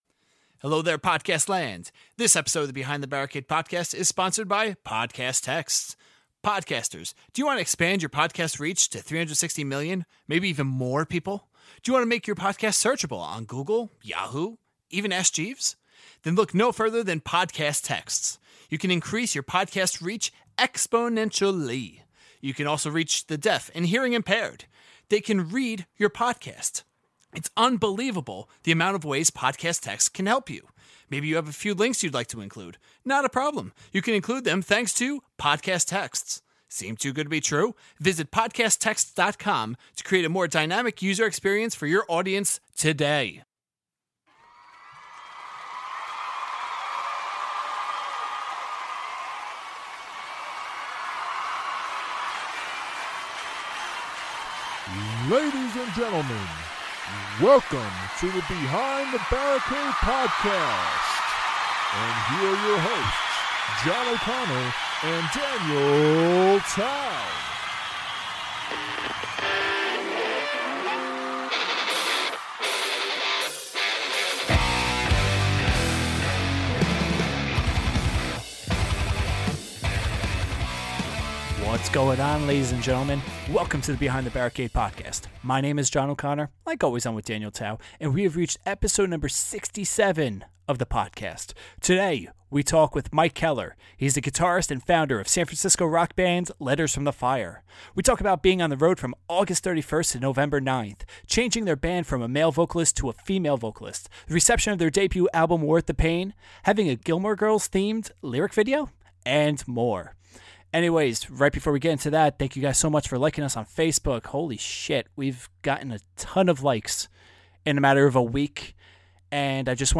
Pre-Interview Song: Give in to Me Post-Interview Song: Worth the Pain